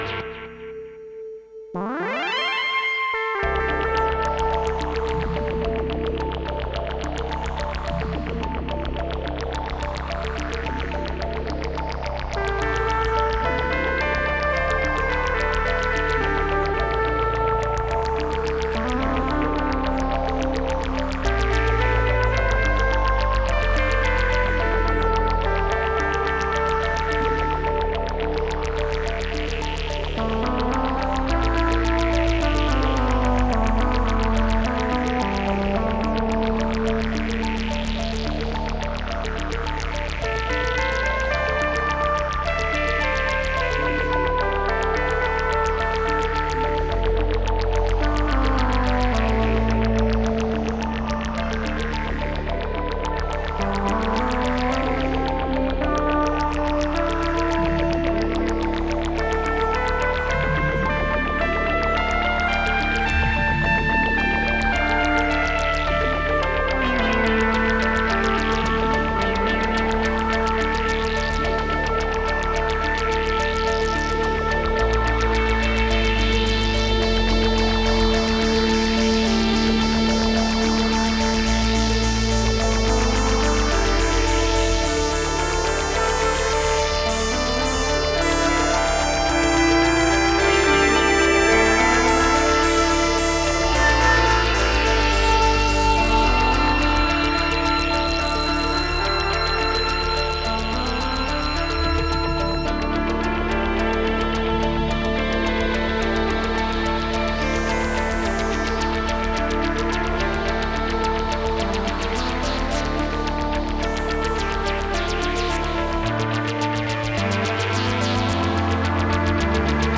Last month I jammed on this thing for a whole night and came up with these tracks, its all CS30 multitracked with some reverb and delay effects:
oodles of dark fun
almost flute like sounds
Strange tunings for otherwordly atmospheres